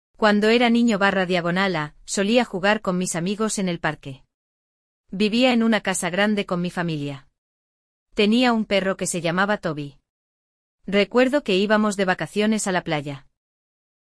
Durante o episódio, acompanhamos um diálogo no qual uma das personagens revisita fotos antigas e relembra momentos marcantes. Você ouvirá como ela descreve pessoas, lugares e experiências, utilizando tempos verbais apropriados para narrar acontecimentos passados.